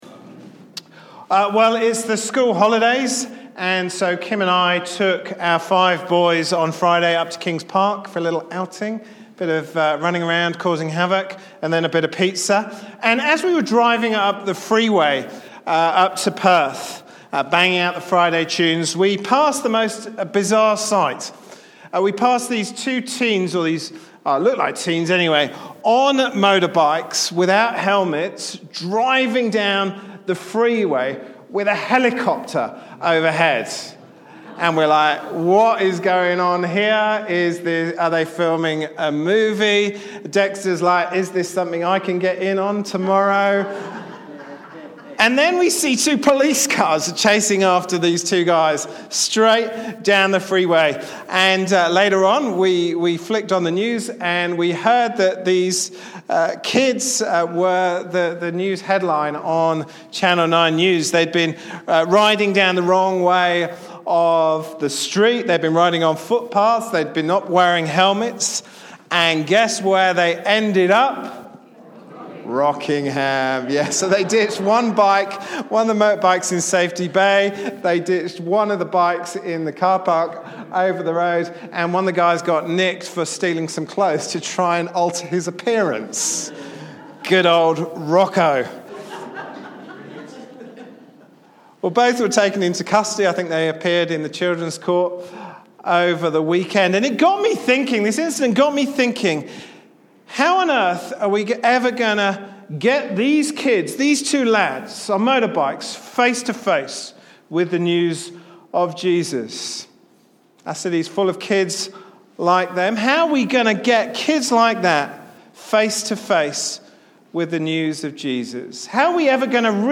Acts 10:1-11:18 Service Type: Sunday morning service Topics